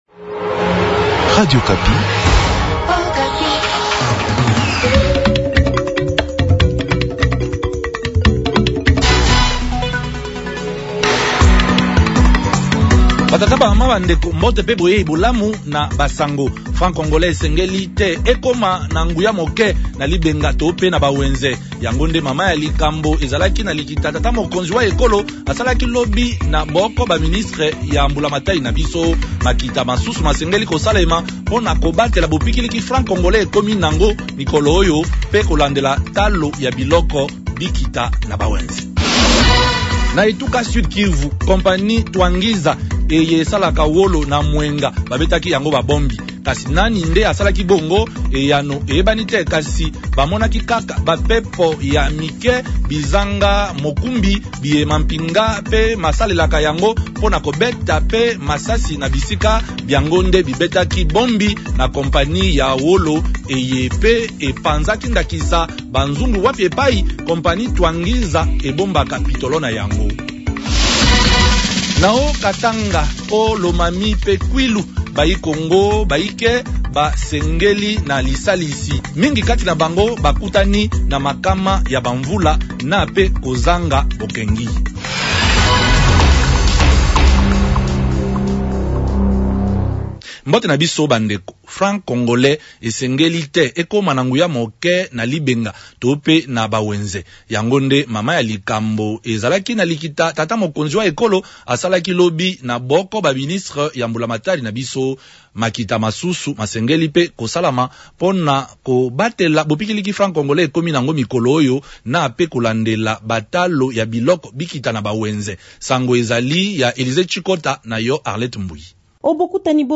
journal lingala